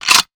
weapon_foley_pickup_25.wav